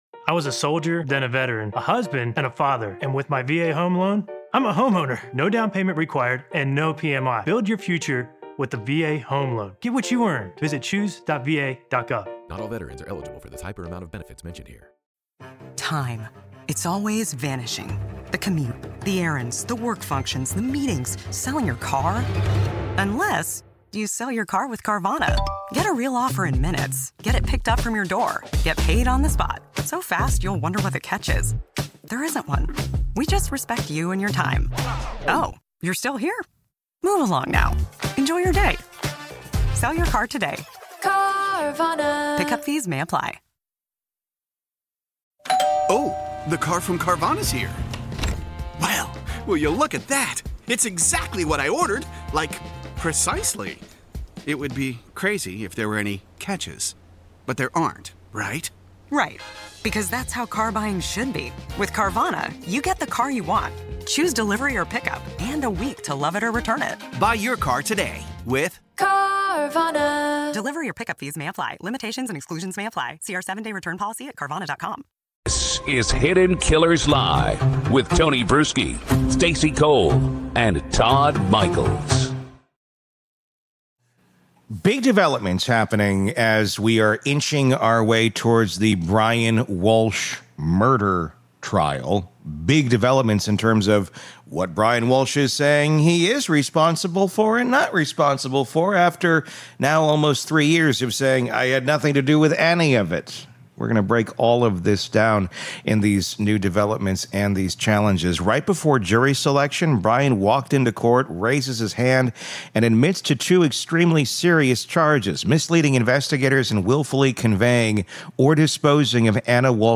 One conversation that lays out the stakes, the law, and the fallout.